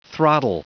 Prononciation du mot throttle en anglais (fichier audio)
Prononciation du mot : throttle